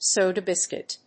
アクセントsóda bíscuit